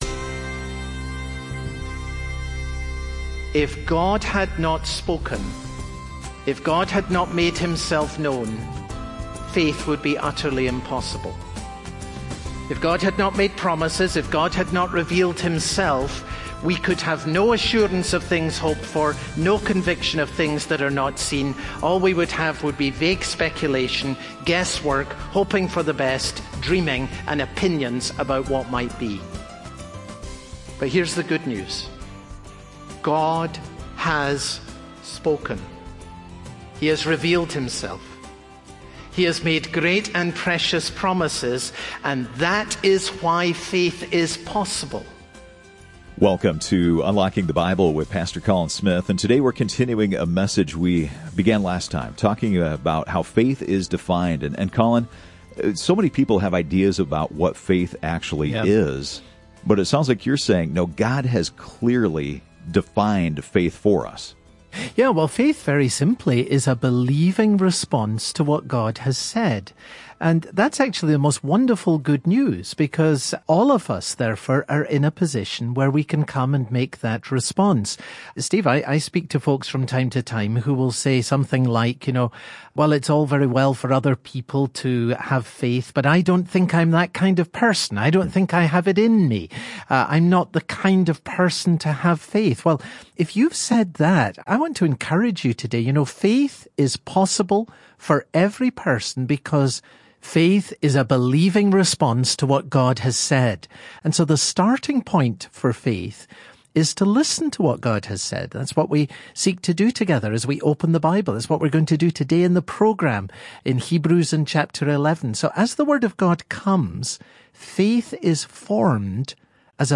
Part 2 Hebrews Broadcast Details Date Jan 05